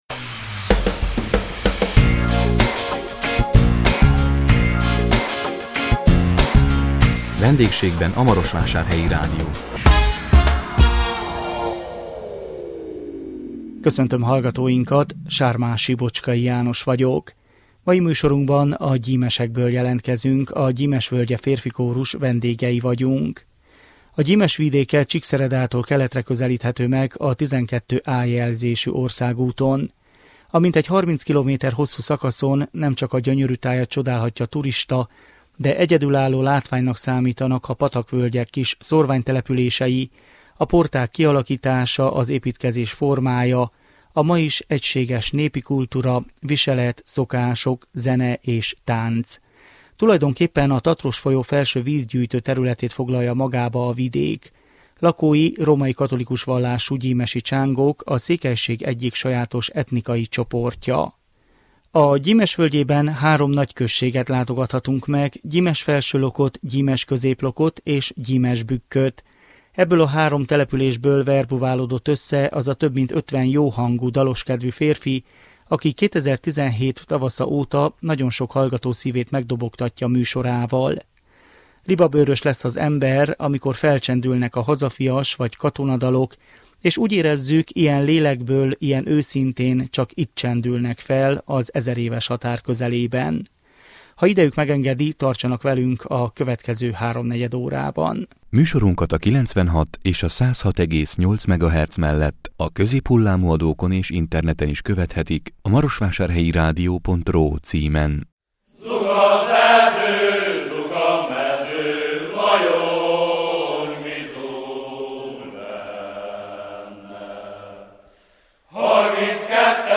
A 2017 szeptember 21-én jelentkező Vendégségben a Marosvásárhelyi Rádió című műsorunkban a Gyimesekből jelentkeztünk, a Gyimes Völgye Férfikórus vendégei voltunk.
Ebből a három településből verbuválódott össze az a több mint ötven jó hangú, dalos kedvű férfi, aki 2017 tavasza óta nagyon sok hallgató szívét megdobogtatja műsorával. Libabőrös lesz az ember, amikor felcsendülnek a hazafias- vagy katonadalok, és úgy érezzük ilyen lélekből, ilyen őszintén csak itt csendülnek fel az ezeréves határ közelében.